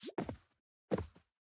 Minecraft Version Minecraft Version 25w18a Latest Release | Latest Snapshot 25w18a / assets / minecraft / sounds / mob / armadillo / unroll_start.ogg Compare With Compare With Latest Release | Latest Snapshot
unroll_start.ogg